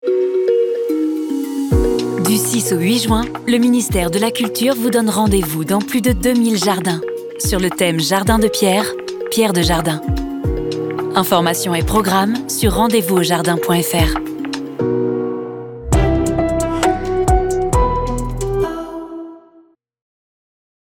Spot France TV pour les Rendez-vous aux Jardins du Ministère de la Culture